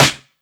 Tuned drums (C# key) Free sound effects and audio clips
• Airy Steel Snare Drum Sound C# Key 83.wav
Royality free steel snare drum sample tuned to the C# note. Loudest frequency: 2839Hz
airy-steel-snare-drum-sound-c-sharp-key-83-QAS.wav